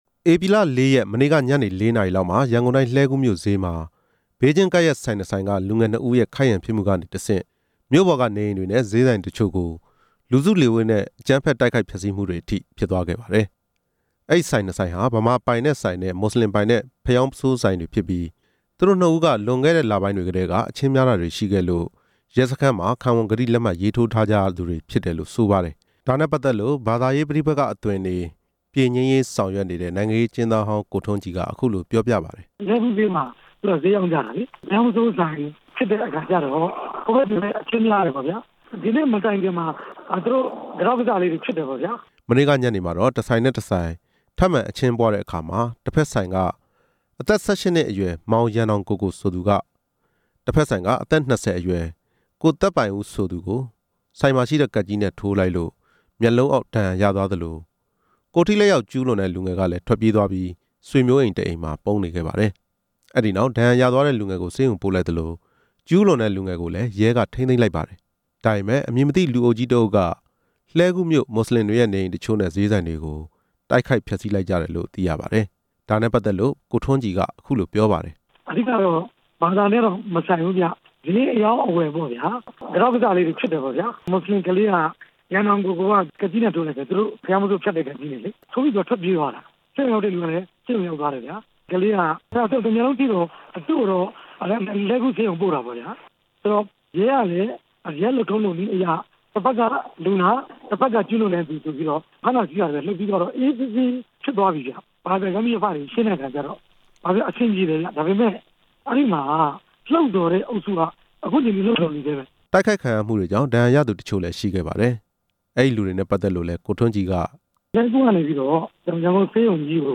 ရန်ကုန်တိုင်း လှည်းကူးမြို့က အကြမ်းဖက်ဆူပူမှု မေးမြန်းချက်